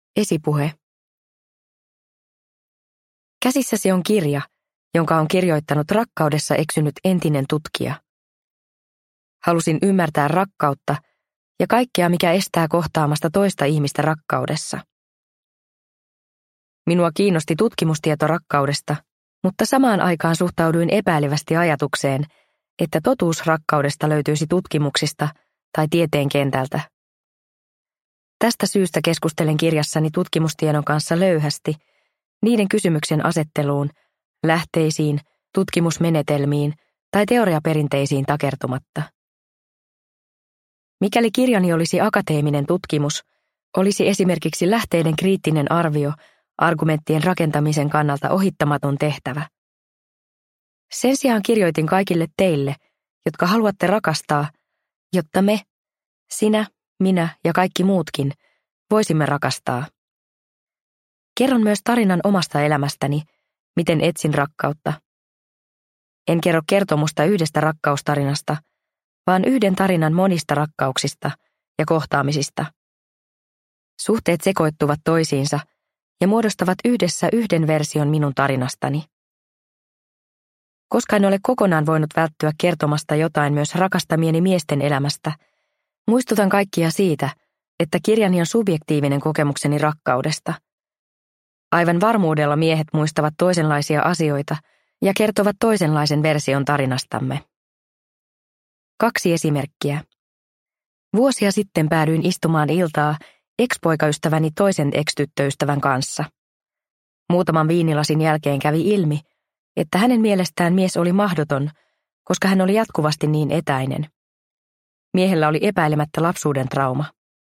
Jotta voisin rakastaa – Ljudbok – Laddas ner